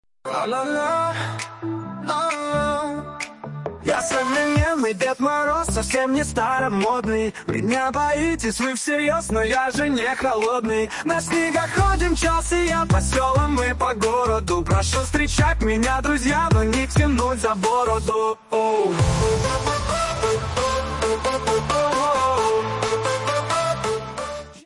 Фрагмент исполнения 2 вариант: